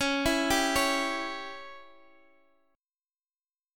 Dbdim Chord